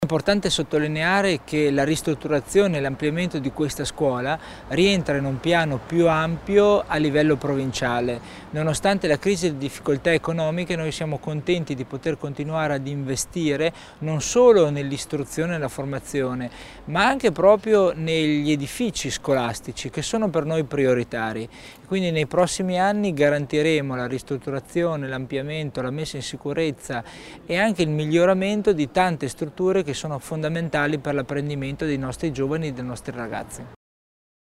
L'Assessore Tommasini spiega l'importanza dell'ampliamento delle scuole Kunter
Oggi (27.11) la posa della 1° pietra con politici, amministratori, tecnici e autorità scolastiche.